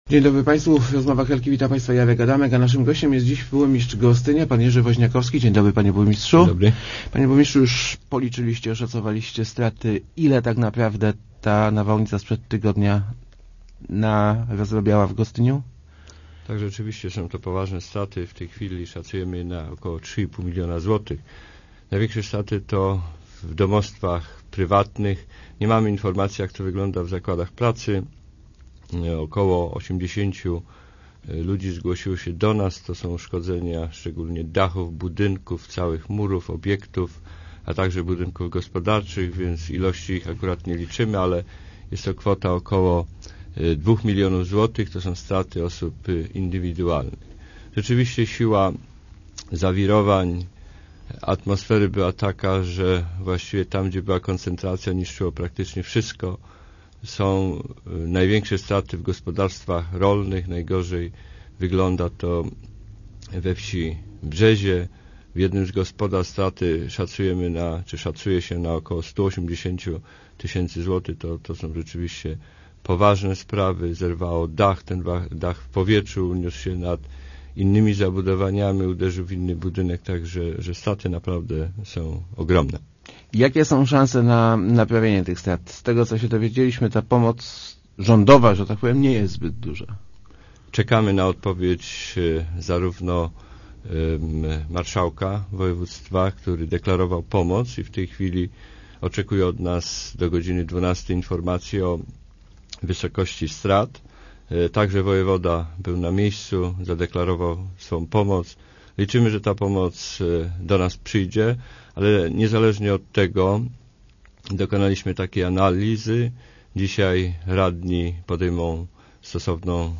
wozniakowski80.jpgStraty mieszkańców gminy spowodowane huraganem to około 2 milionów złotych – powiedział w Rozmowach Elki burmistrz Gostynia Jerzy Woźniakowski.